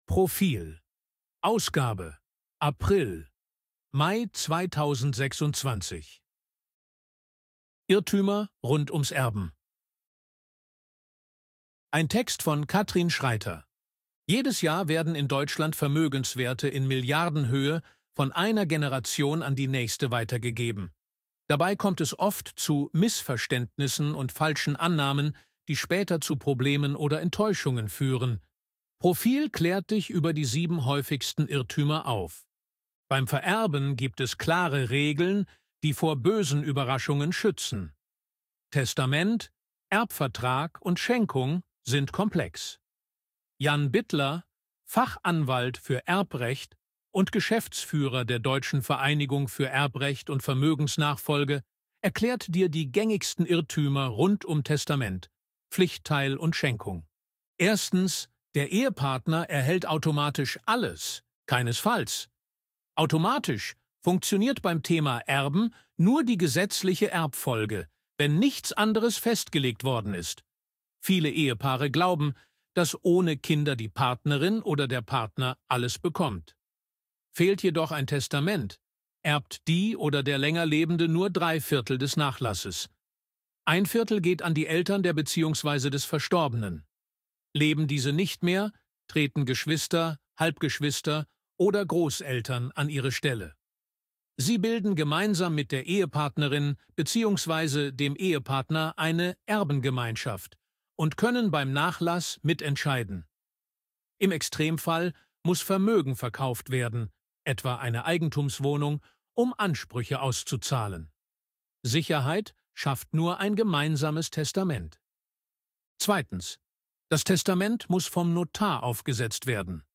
ElevenLabs_262_KI_Stimme_Mann_Service_Leben.ogg